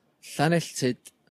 ynganiad ) [1] (hefyd Llanelltyd).